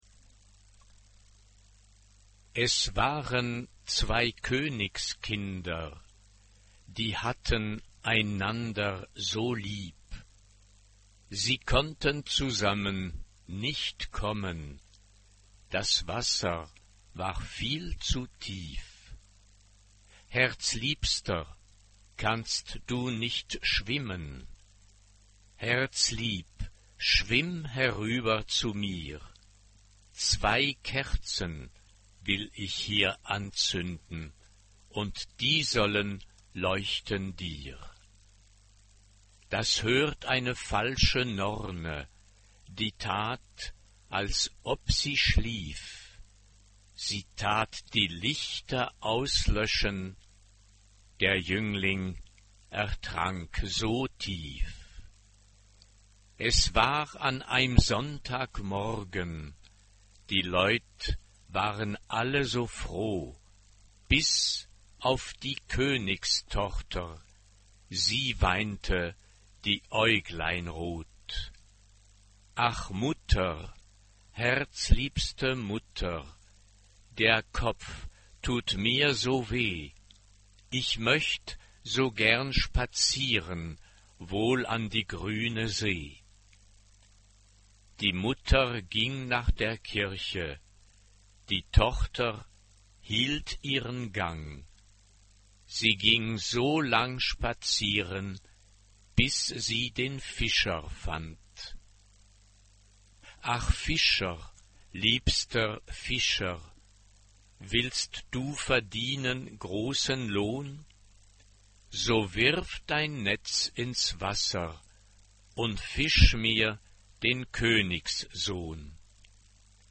SSA (3 voces Coro femenino) ; Partitura general.
Profano ; Popular ; Coro ; Tradicional
Tonalidad : sol mayor